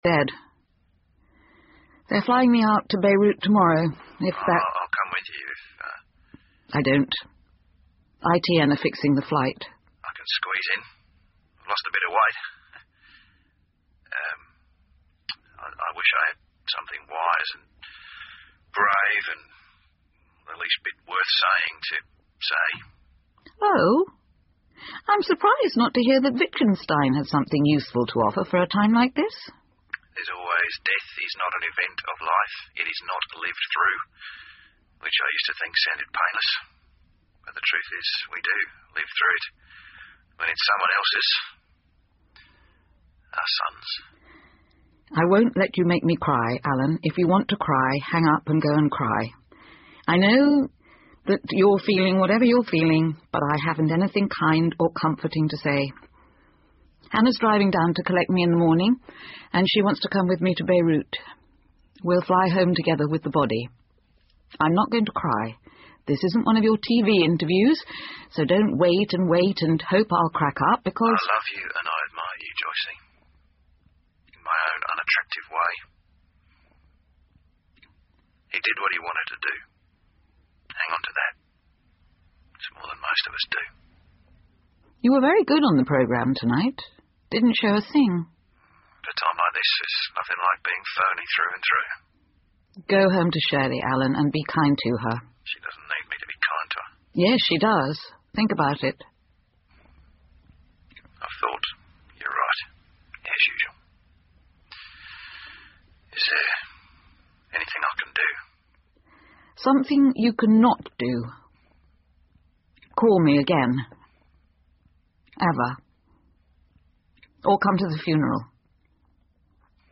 英文广播剧在线听 Fame and Fortune - 38 听力文件下载—在线英语听力室